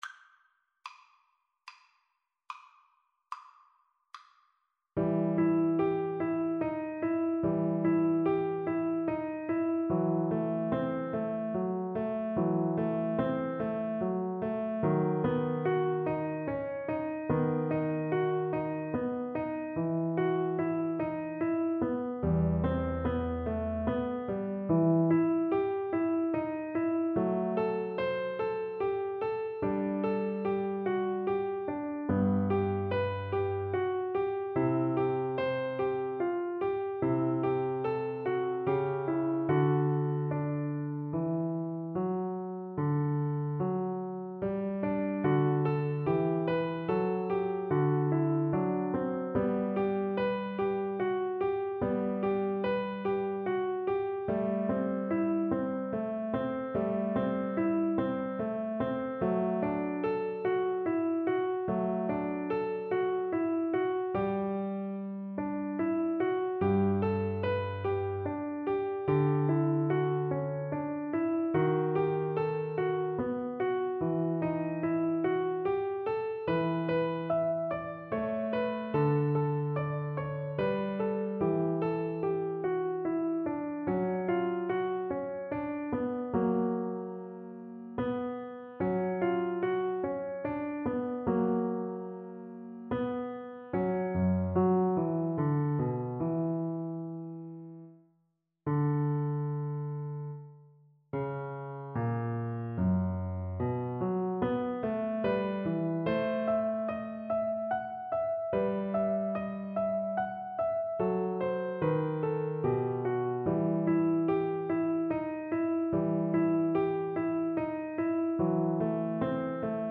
Siciliano =100
Classical (View more Classical Violin Music)